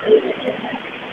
EVP's